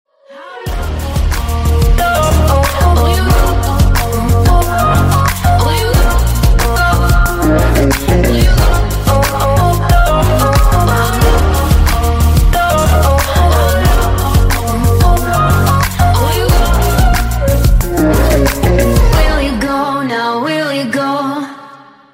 Клубные Рингтоны
Рингтоны Электроника